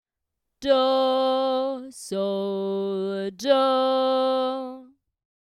Tonic-dominant-tonic, with dominant above tonic